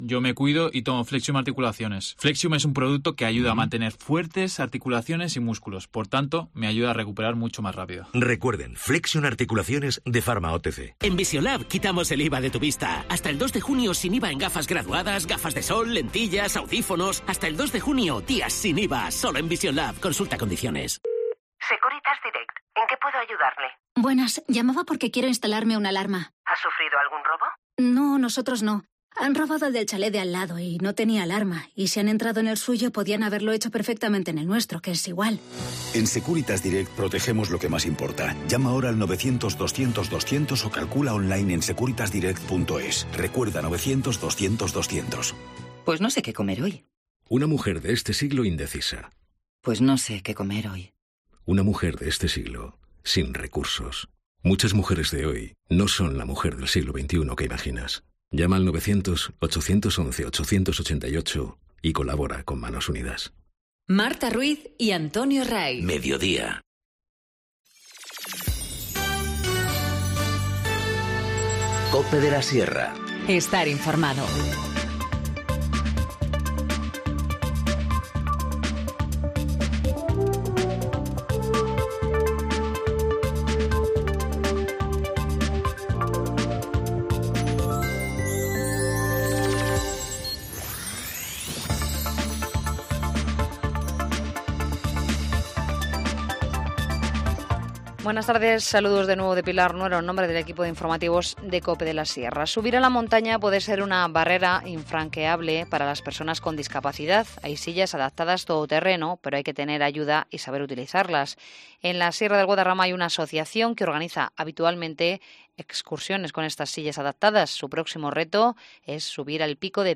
Informativo Mediodía 29 mayo 14:50h
COPE de la Sierra INFORMACIÓN LOCAL